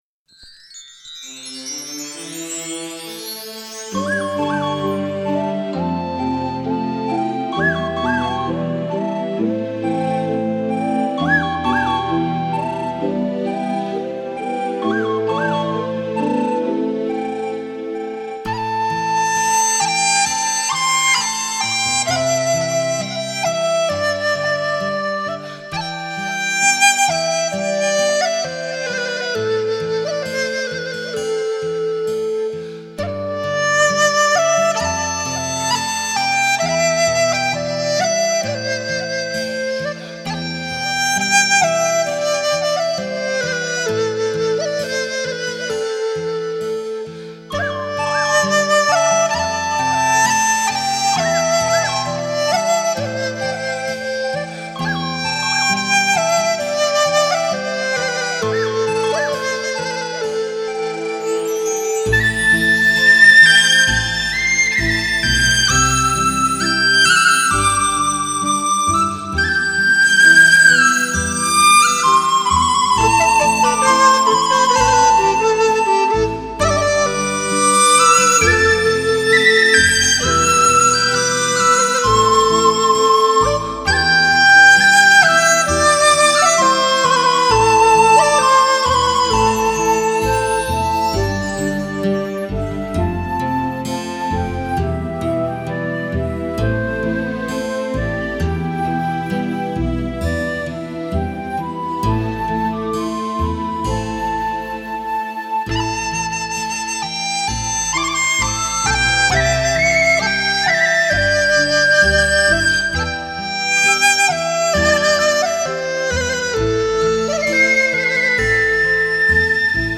以曲笛、梆笛、新笛、口笛、低音笛、木笛为主，辅以领奏、轮奏、二重奏、三重奏加之背景技巧融为一体的全新演绎手法。